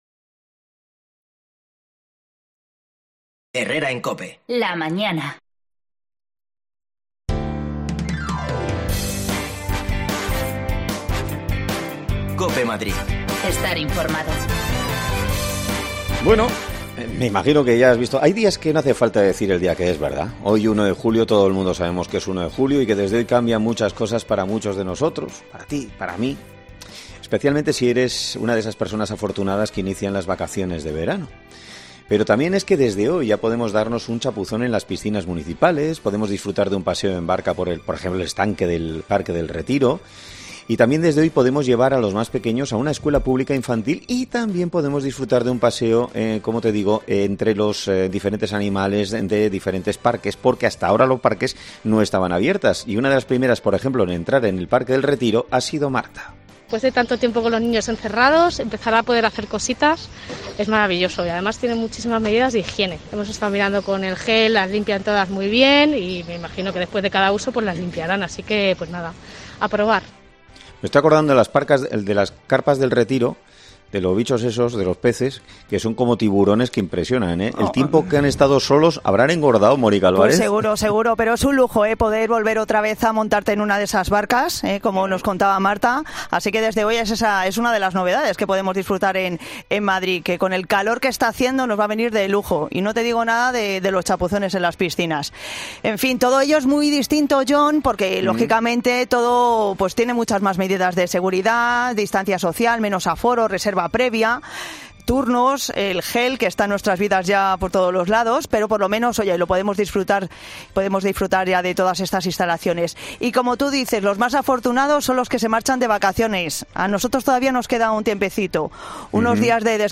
Te contamos la actualidad de Madrid en este 1 de julio en el que muchos madrileños inician sus vacaciones de verano. Nos hemos acercado a la estación de Atocha
Las desconexiones locales de Madrid son espacios de 10 minutos de duración que se emiten en COPE , de lunes a viernes.